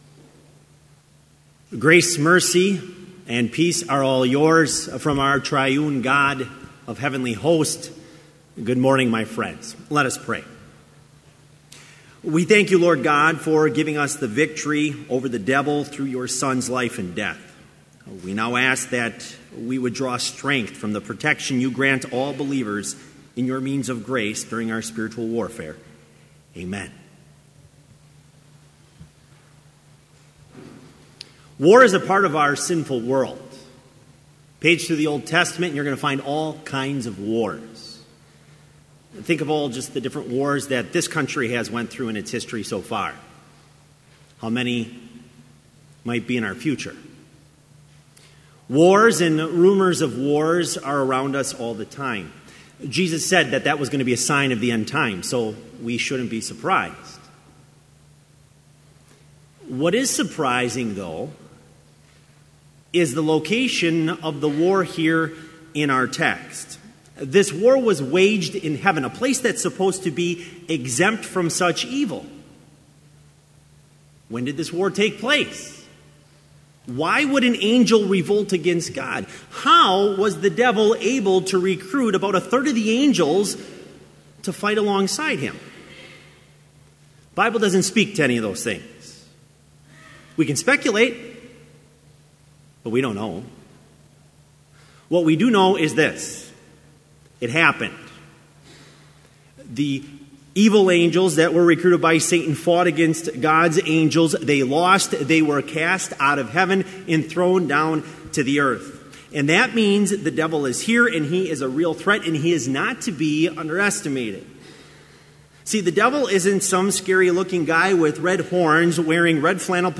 Complete service audio for Chapel - September 29, 2017